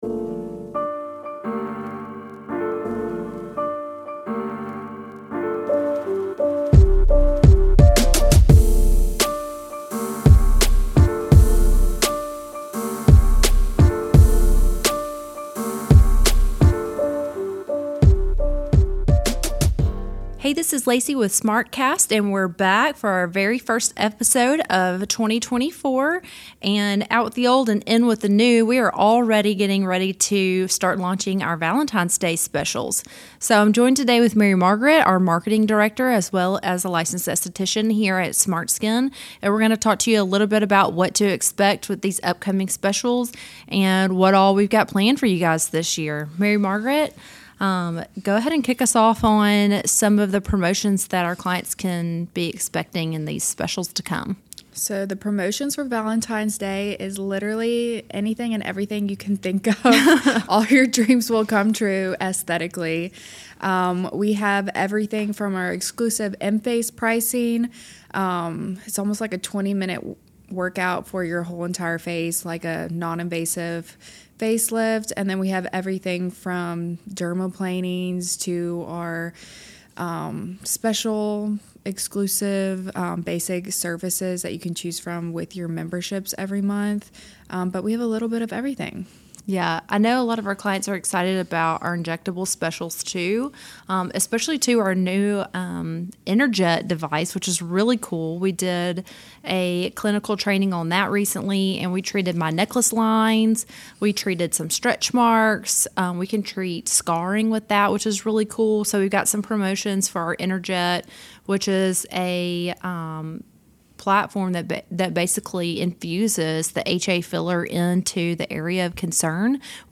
Stay tuned for a fun and insightful conversation!